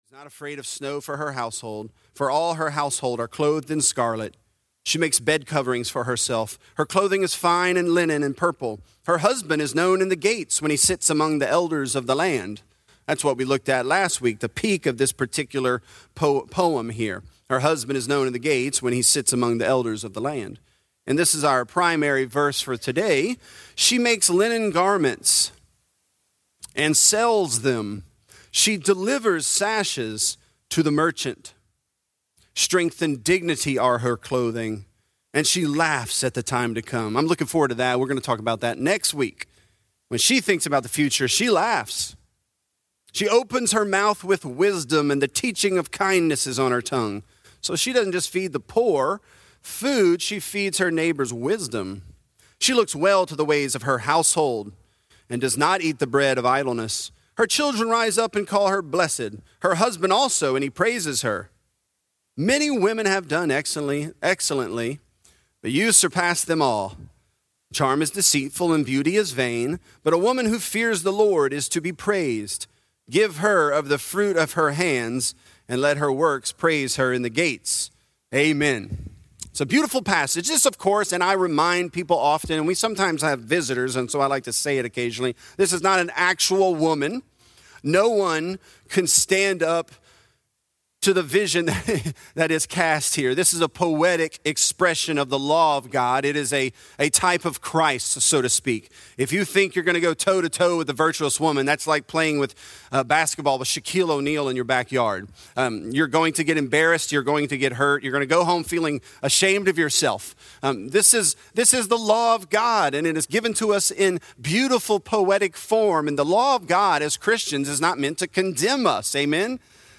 Virtuous: She Makes Linen Garments | Lafayette - Sermon (Proverbs 31)